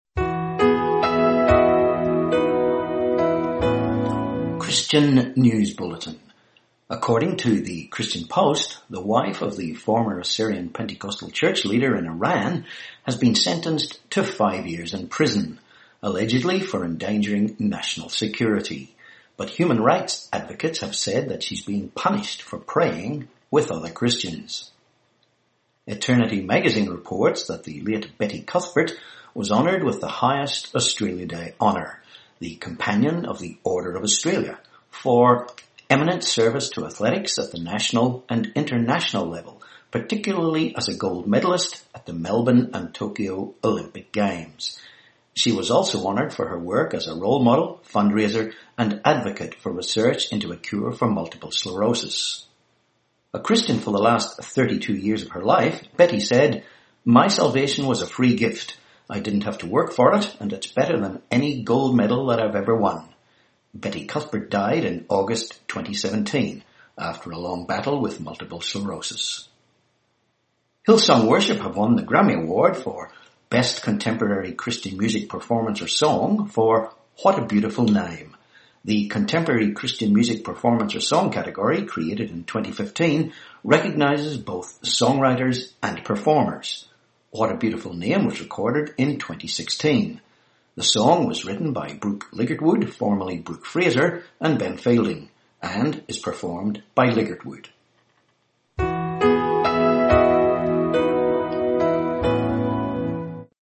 4Feb18 Christian News Bulletin